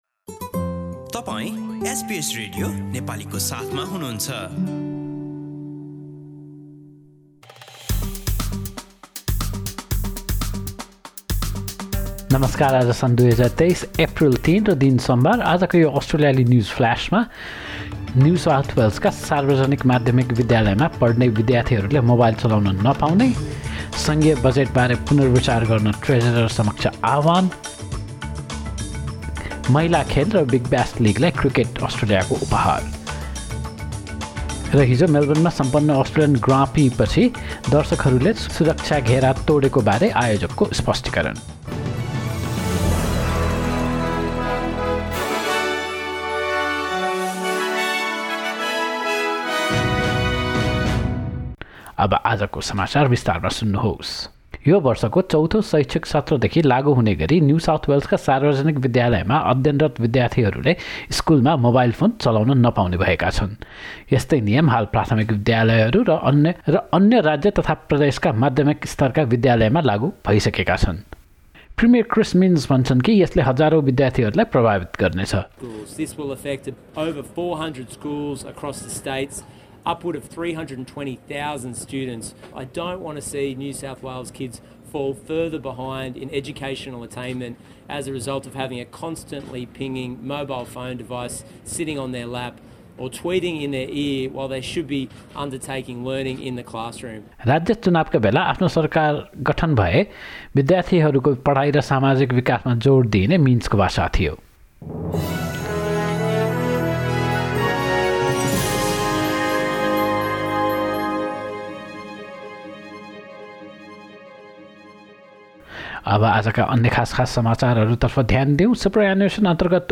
एसबीएस नेपाली अस्ट्रेलिया न्युजफ्लास: सोमवार ३ एप्रिल २०२३